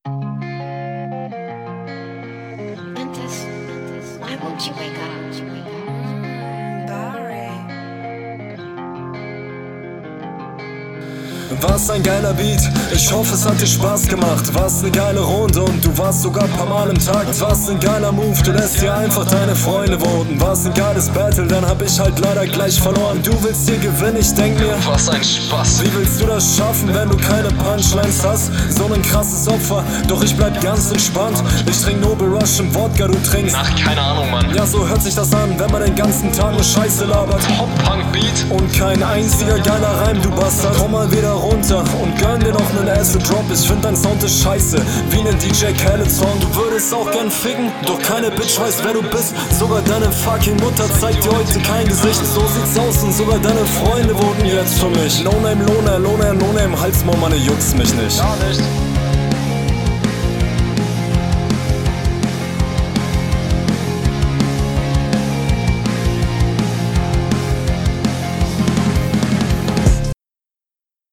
wirkt echt nicht onpoint teilweise halt relativ ernst gekontert und relativ basic gerappt aber wirkt …